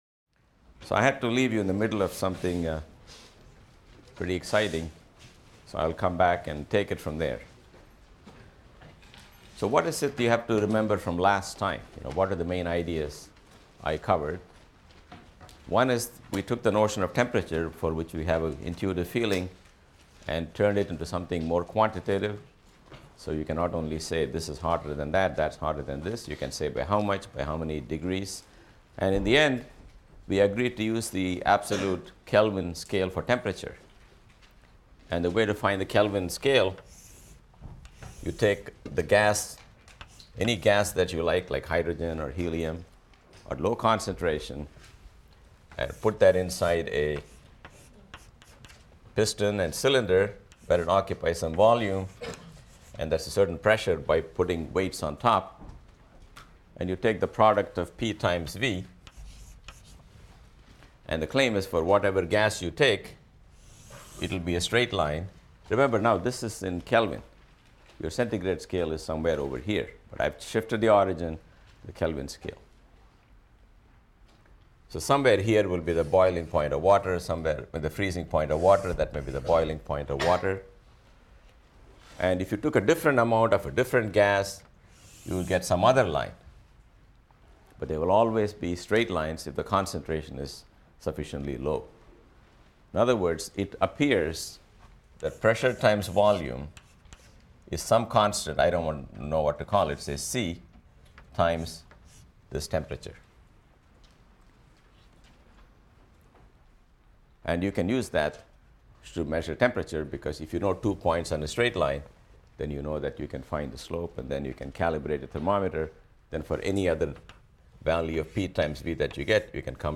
PHYS 200 - Lecture 22 - The Boltzmann Constant and First Law of Thermodynamics | Open Yale Courses